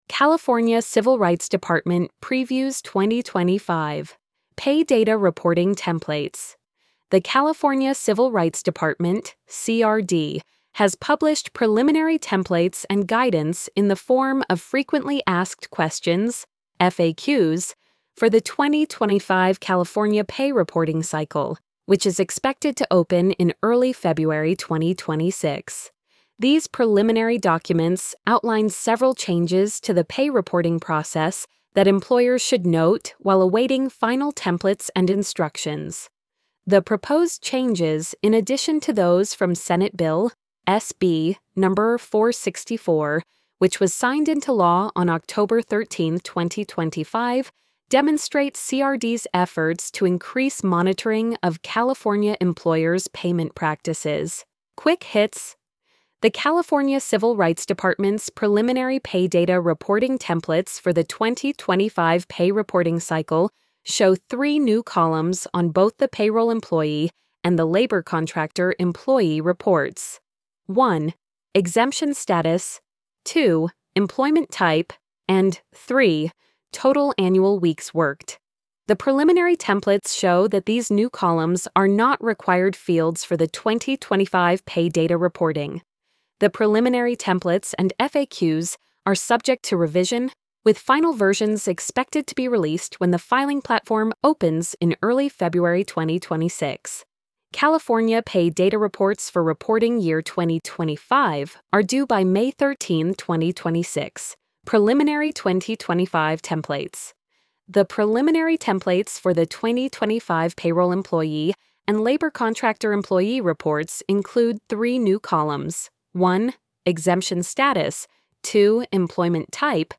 california-civil-rights-department-previews-2025-pay-data-reporting-templates-tts-1.mp3